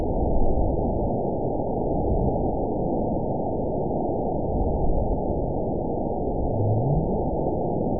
event 912624 date 03/30/22 time 12:47:55 GMT (3 years, 1 month ago) score 9.61 location TSS-AB04 detected by nrw target species NRW annotations +NRW Spectrogram: Frequency (kHz) vs. Time (s) audio not available .wav